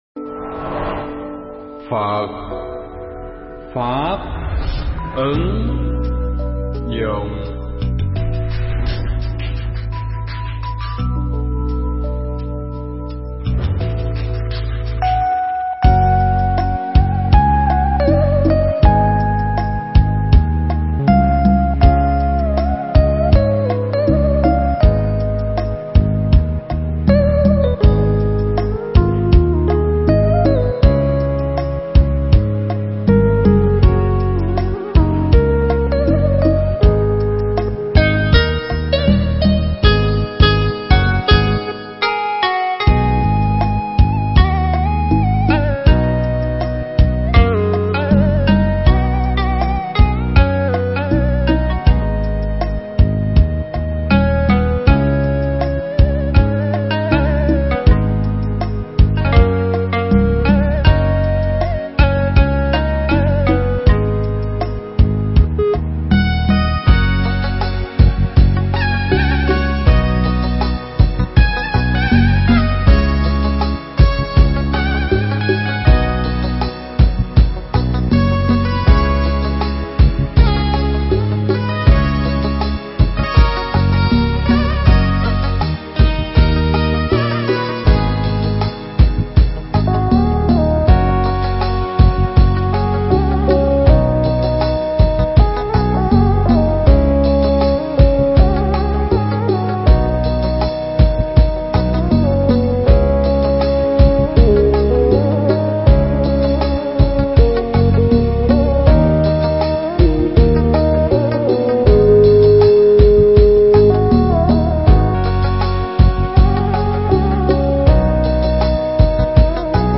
Thuyết pháp Buồn Đáng Sợ Hơn Cả Cái Chết
thuyết giảng tại chùa Linh Sơn (Hà Lan)